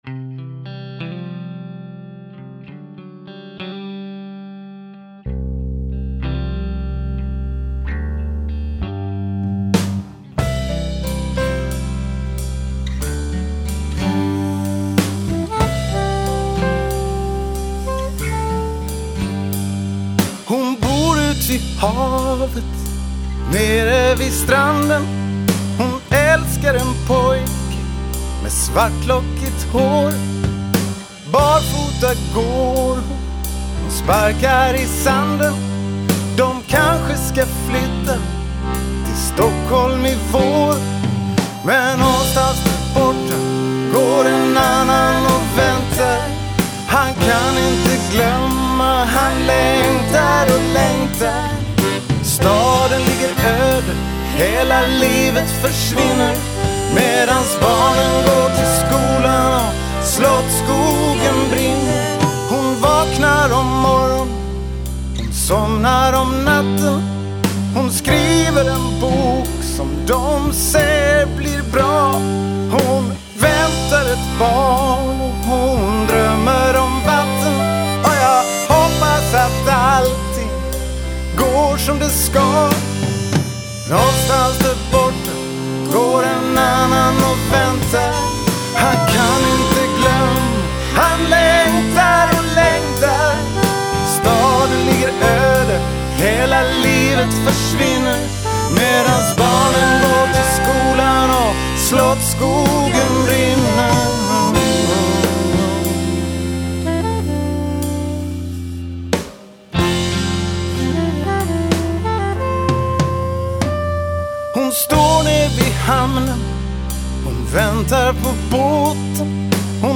Det svänger inte så farligt...